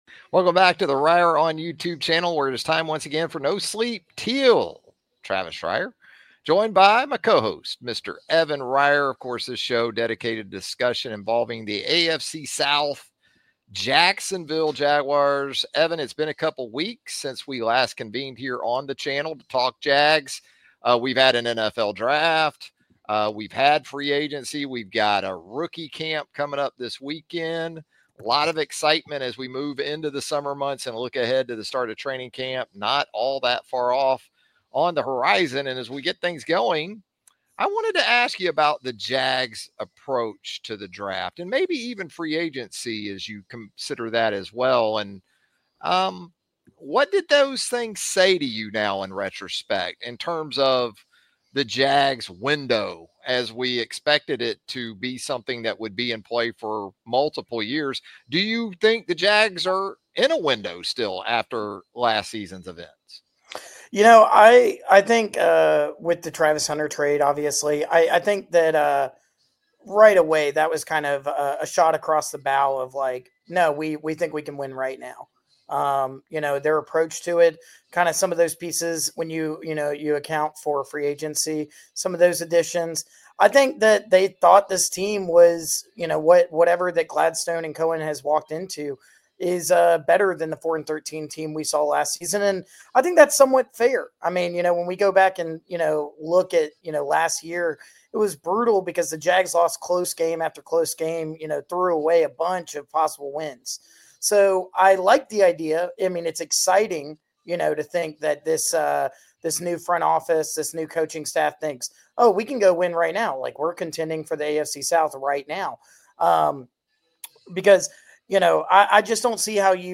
Welcome back to another solo episode of SANDCAST